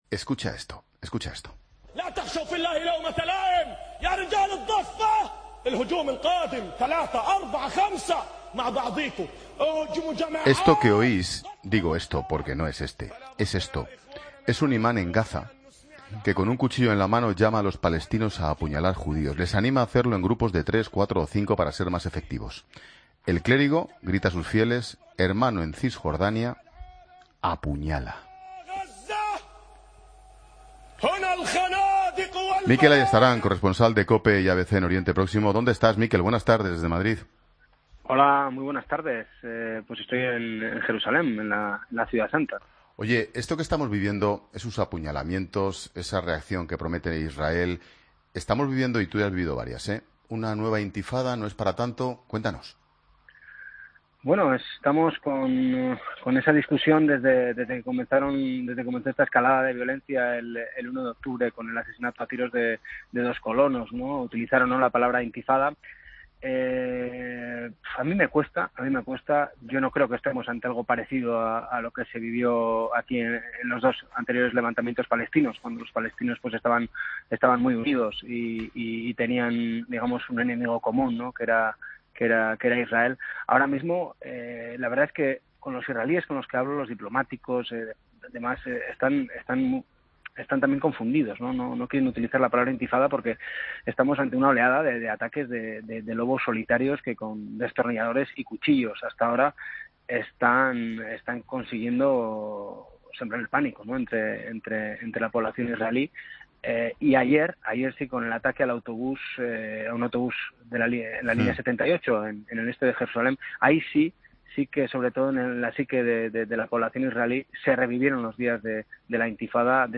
nos ofrece una crónica de la situación que se vive desde Jerusalén en medio de la ola de violencia que se está viviendo en diferentes puntos de Israel.